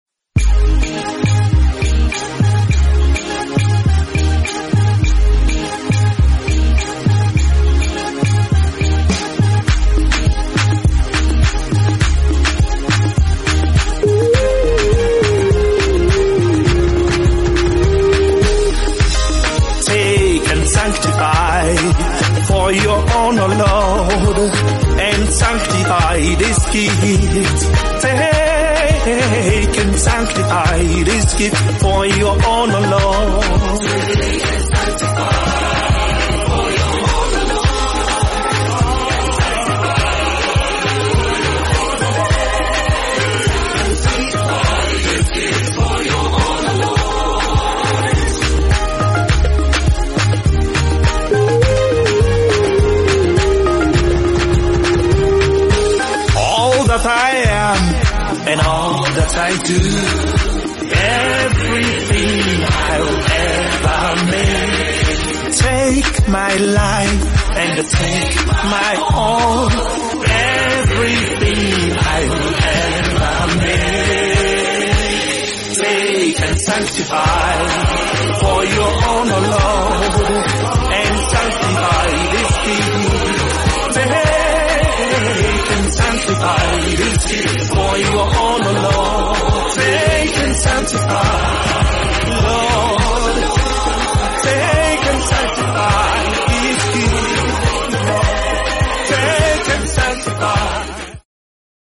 CATHOLIC HYMN
Performed by Immaculate Heart of Mary Brc, Onitsha , Anambra.